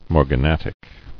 [mor·ga·nat·ic]